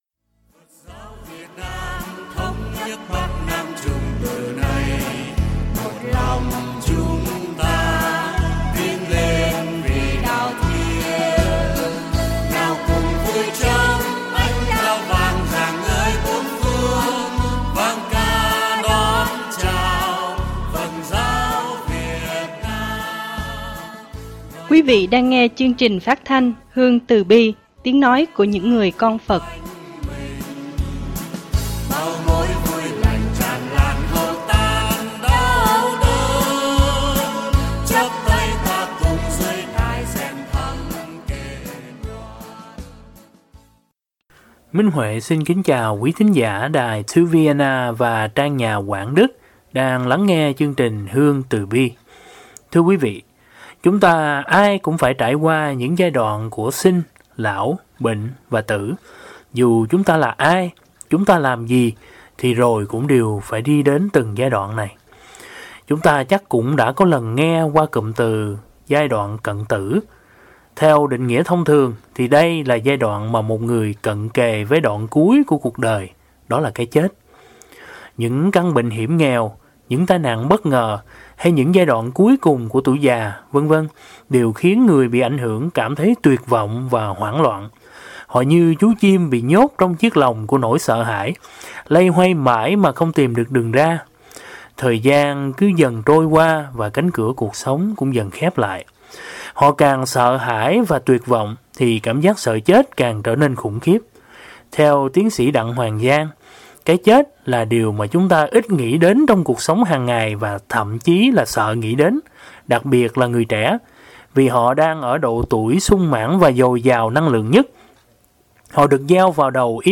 pháp thoại